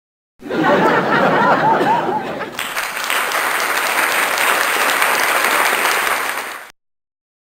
دانلود آهنگ خندیدن جمعیت و مردم 5 از افکت صوتی انسان و موجودات زنده
دانلود صدای خندیدن جمعیت و مردم 5 از ساعد نیوز با لینک مستقیم و کیفیت بالا
جلوه های صوتی